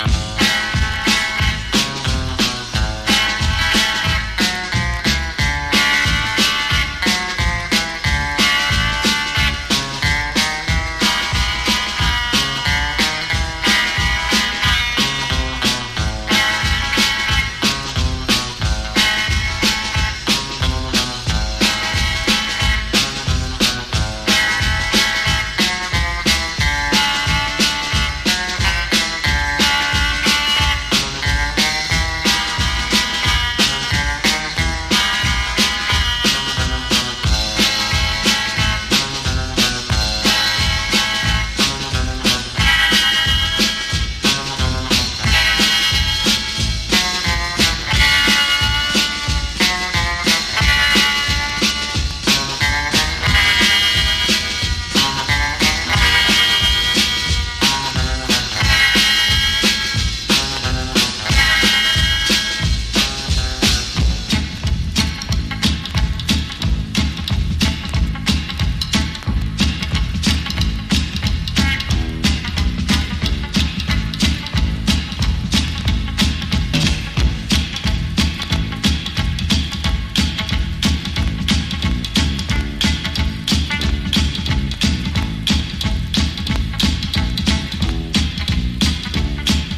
ファンキー・ドラム・インストロ・クラシック！